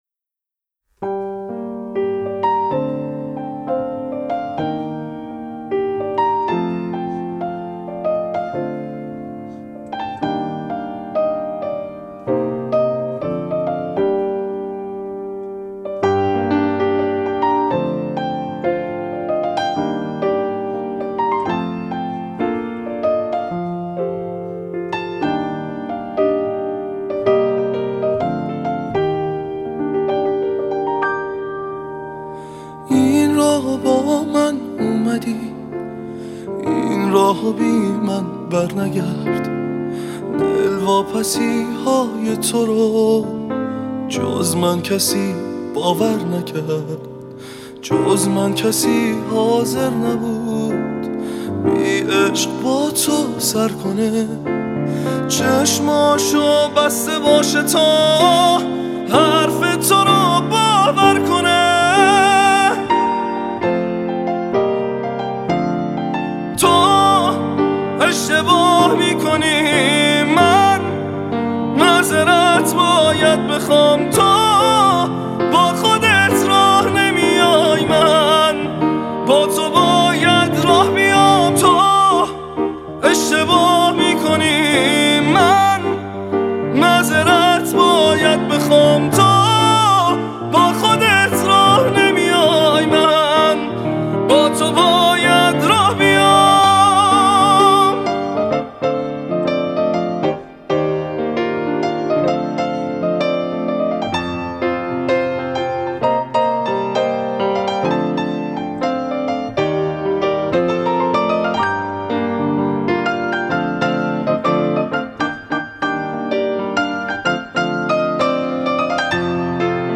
پیانو